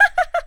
taunt1.ogg